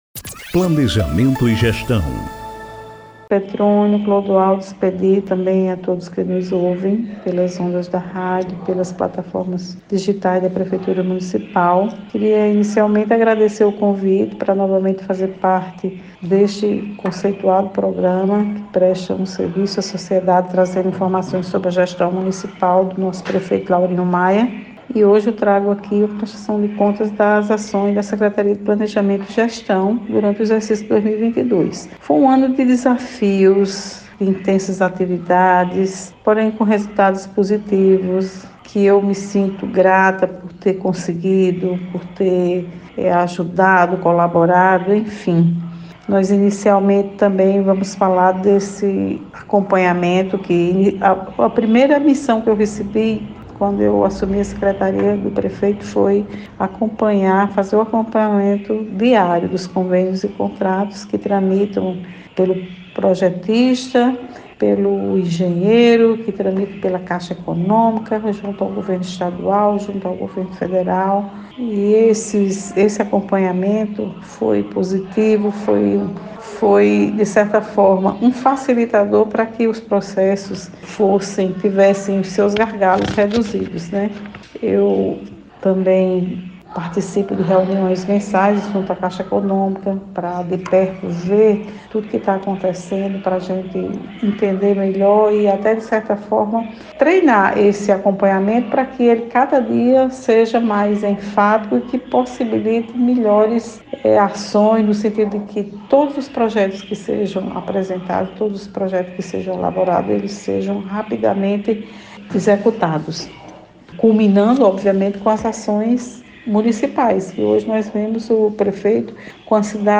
Ouça a Secretária Municipal de Planejamento e Gestão Eriene Rafael: